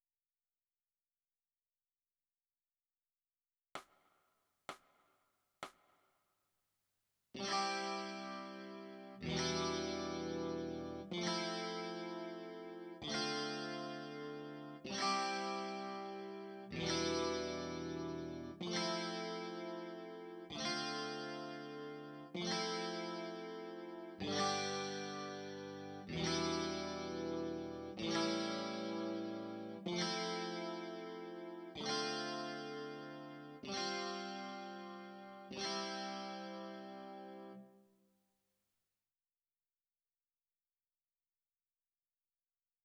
※曲の中には、無音部分が入っていることもあります。